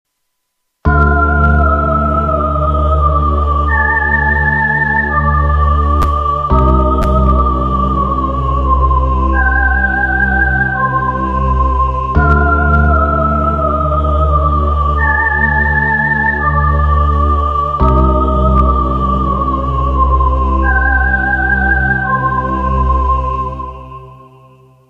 （同じフレーズを２回繰り返して２５秒。ノイズあり）
主旋律は女性の声かテルミン的なものだったかと。
↑この再現のは大して怖くないですが、ずっと頭に残っているフレーズです。